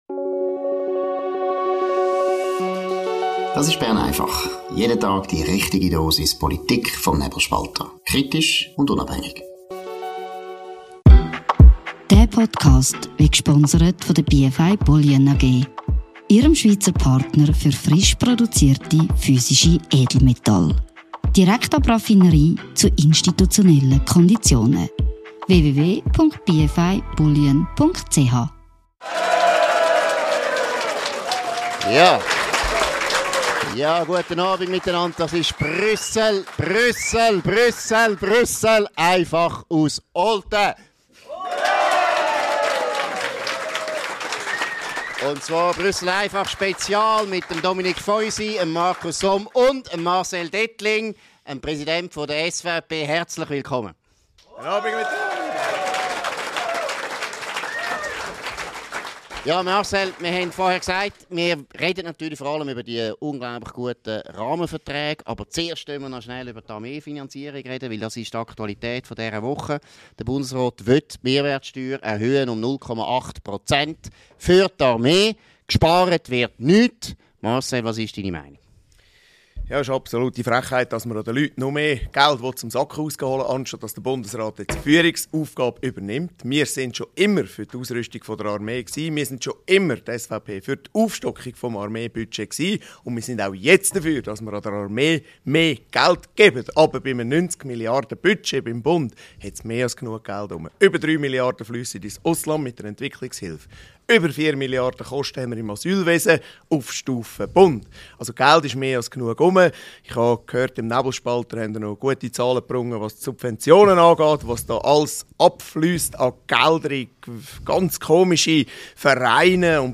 Download Live aus Olten